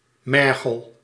Ääntäminen
Ääntäminen France (Île-de-France): IPA: /maʁn/ Haettu sana löytyi näillä lähdekielillä: ranska Käännös Konteksti Ääninäyte Substantiivit 1. mergel {m} geologia Suku: f .